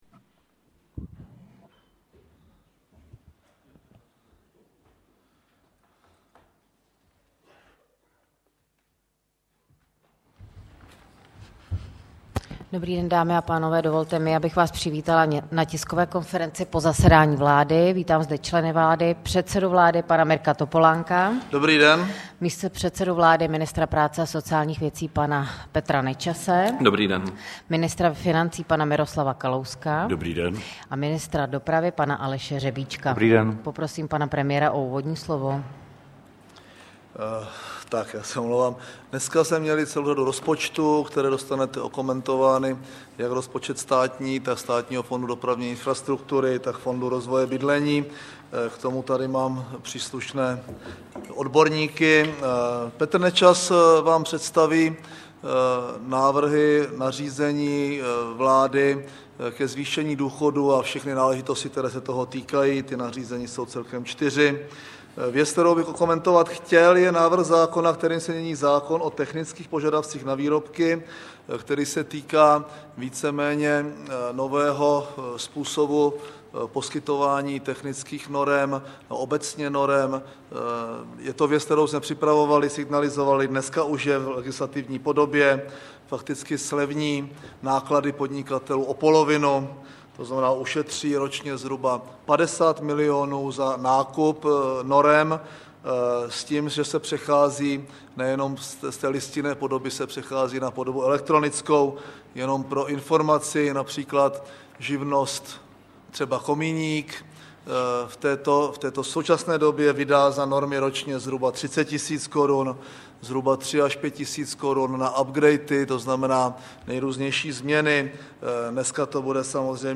Tisková konference po jednání vlády 22. září 2008